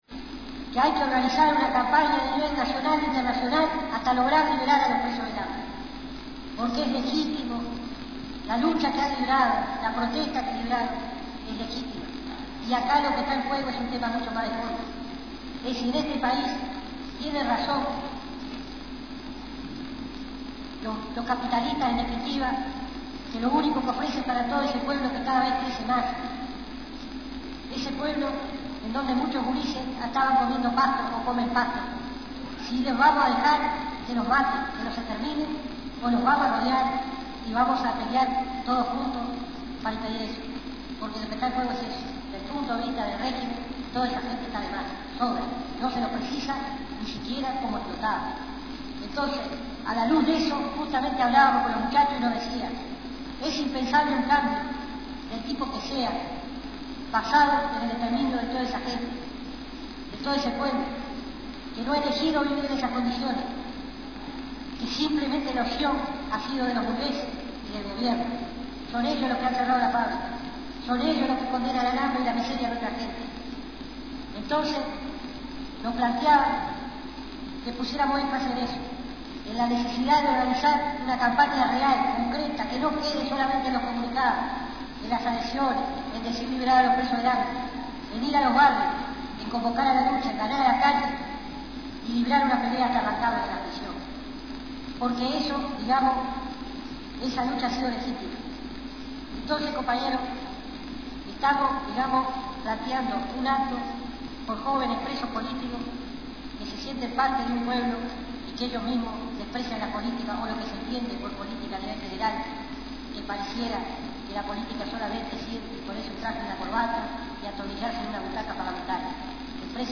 A continuación extractos grabados por Indymedia/Uruguay de las palabras dichas por